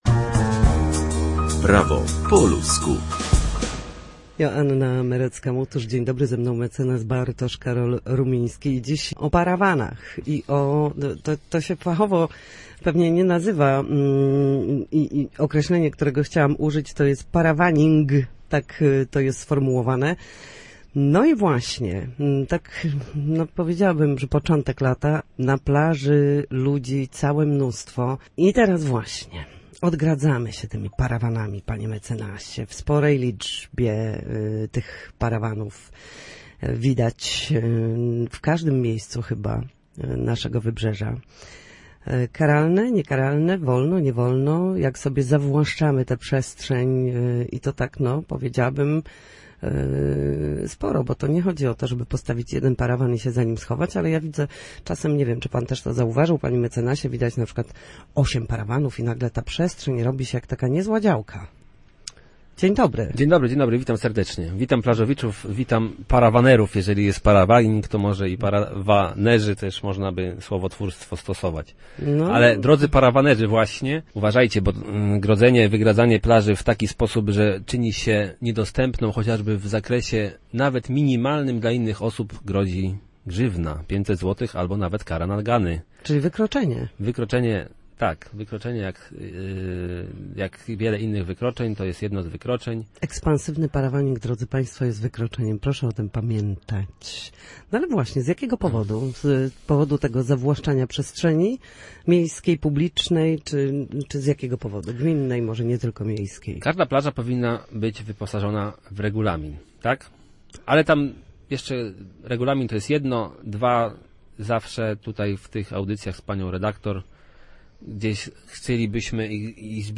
W każdy wtorek o godzinie 13:40 na antenie Studia Słupsk przybliżamy państwu meandry prawa. Nasi goście – prawnicy – odpowiadają na jedno wybrane pytanie dotyczące zachowania w sądzie lub podstawowych zagadnień prawnych.